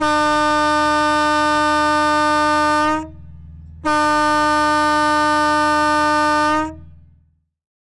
transport
Fire Fighting Boat Horn 1